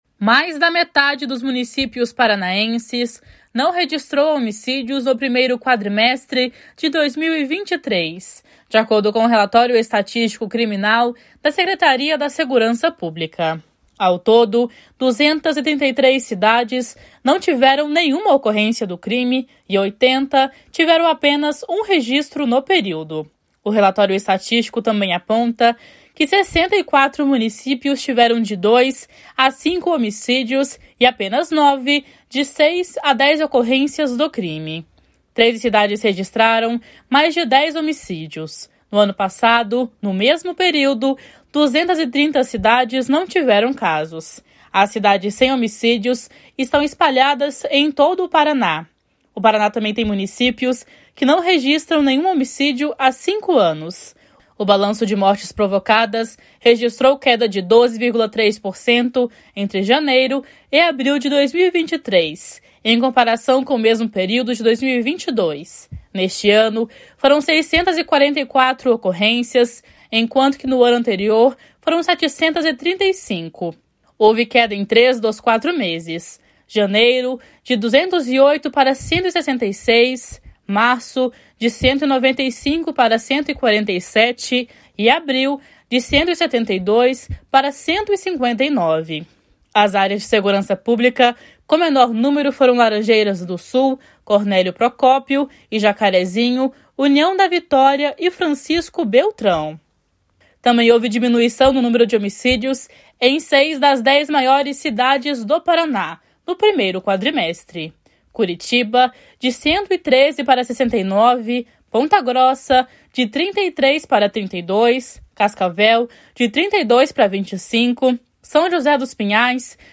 // SONORA HUDSON LEÔNCIO //